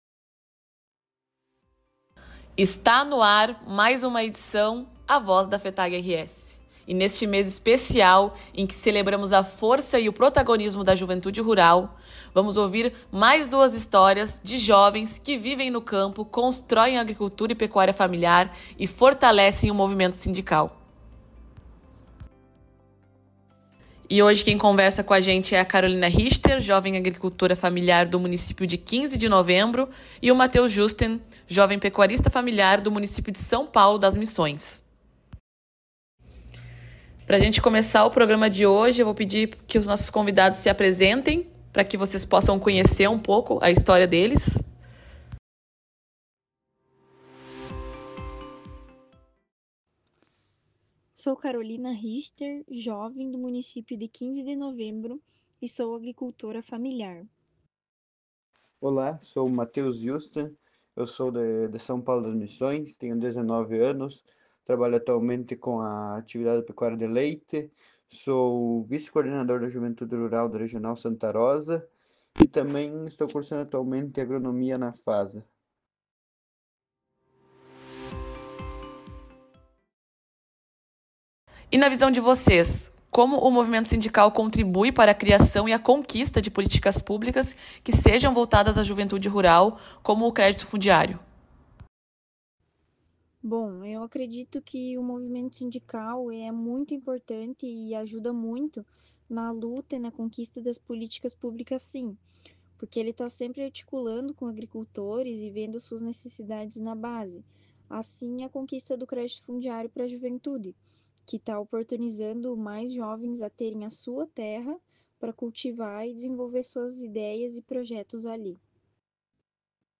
A cada programa, dois jovens agricultores e dirigentes da juventude sindical vão compartilhar suas histórias, seus sonhos e o que os inspira a seguir firmes na luta por um campo vivo e com futuro.